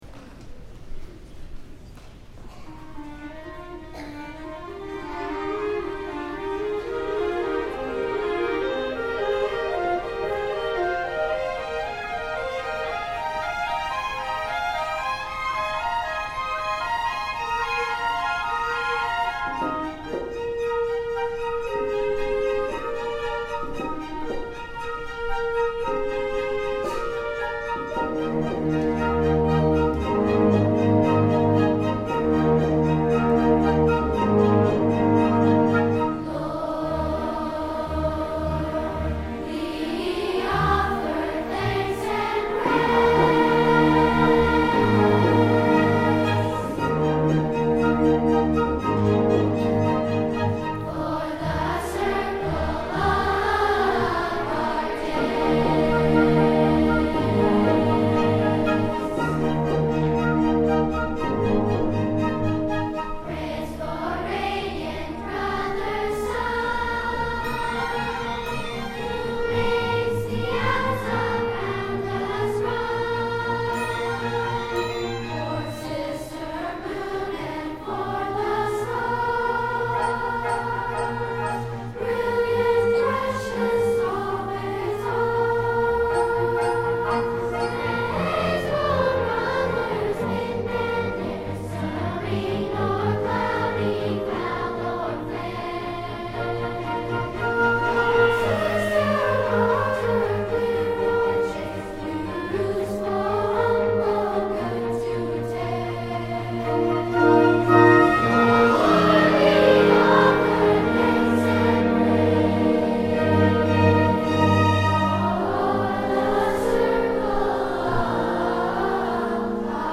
for Two-Part Treble Chorus and Orchestra (2010)